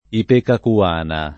ipecacuana [ ipekaku- # na ]